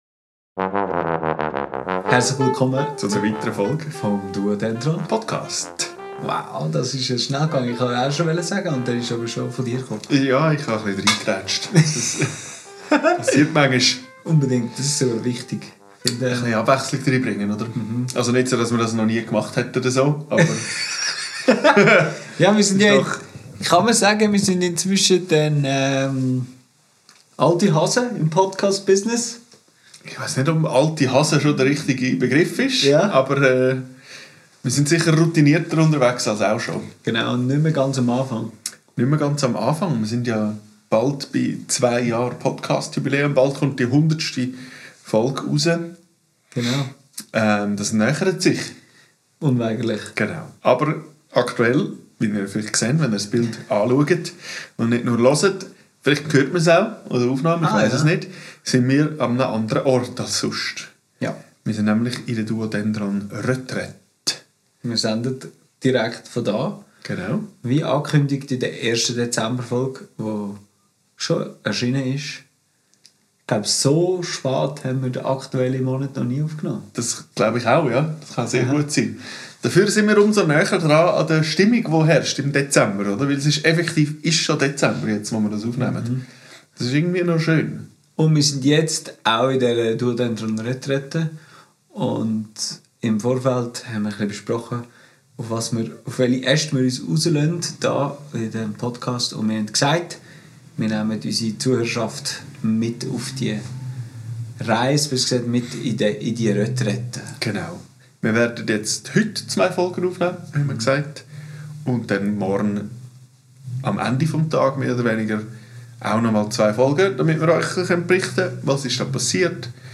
Wir sind in unserer jährlichen duodendron RETRAITE! Was das heisst, was unser Plan ist und womit wir uns sonst gerade so beschäftigen erfahrt ihr in dieser Folge. Aufgenommen am 03.12.2025 in Rovio.